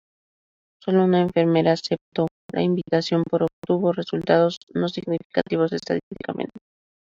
in‧vi‧ta‧ción
/imbitaˈθjon/